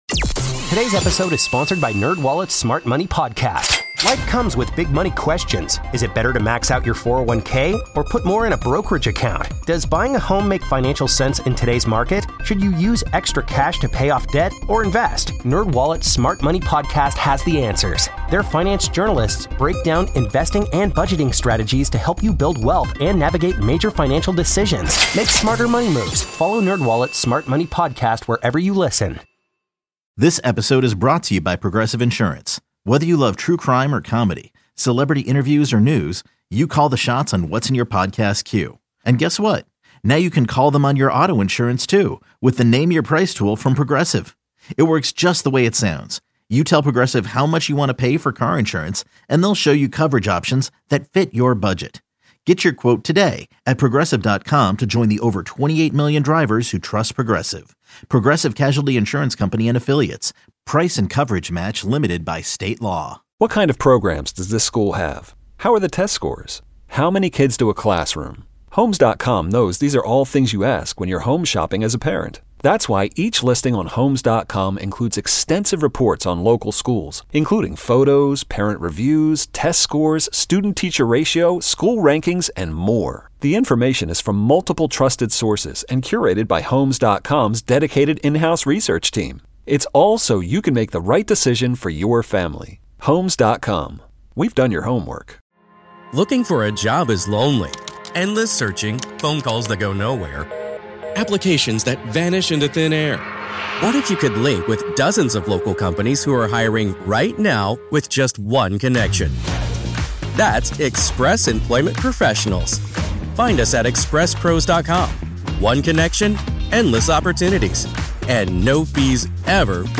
playing back a clip from Professor Alan Dershowitz